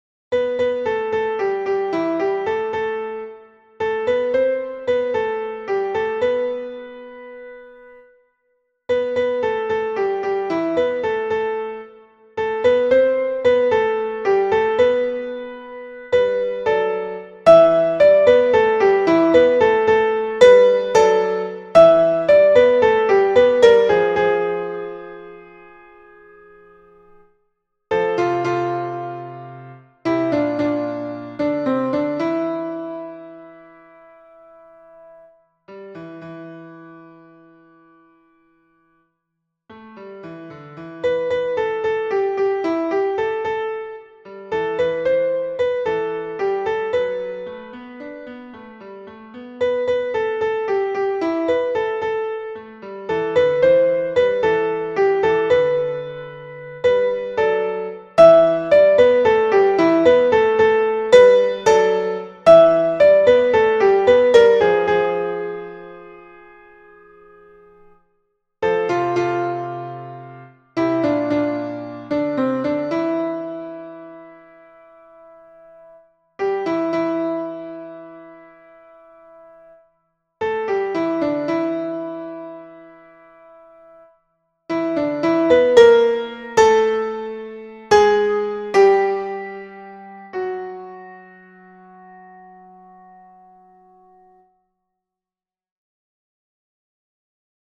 Alto et autres voix en ariière-plan